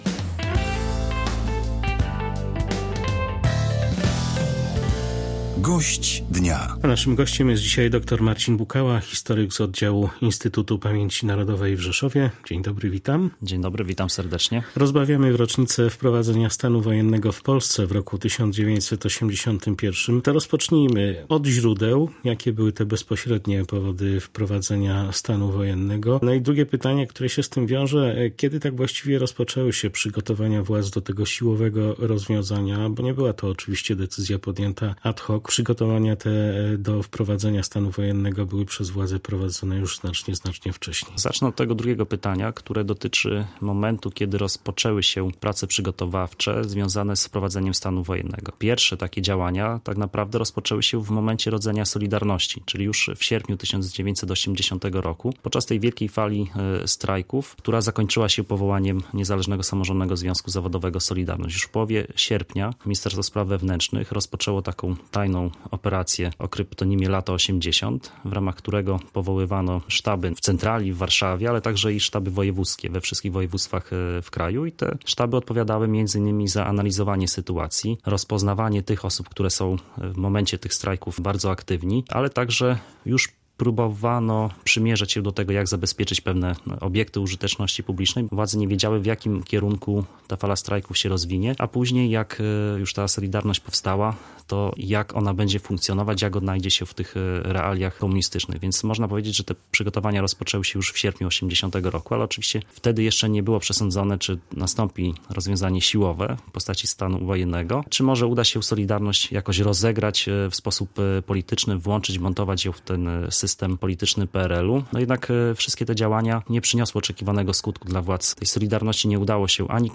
13_12_gosc_dnia.mp3